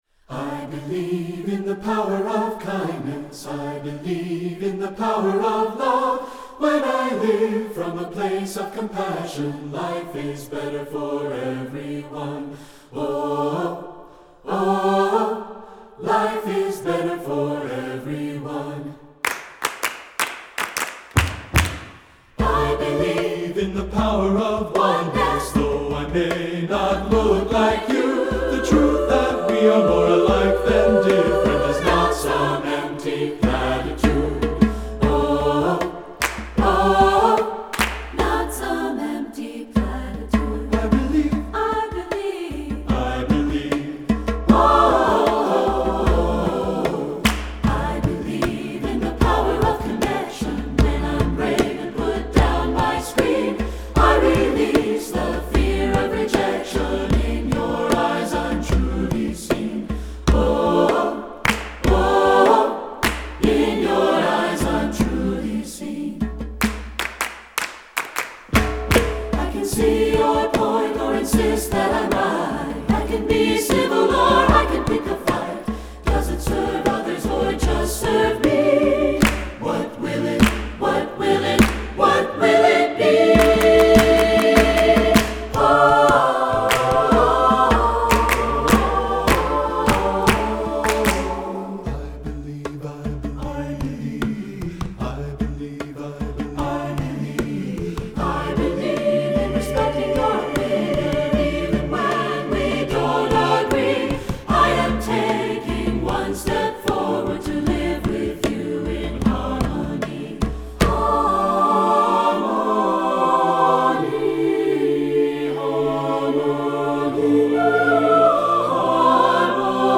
Voicing: SATB divisi and Percussion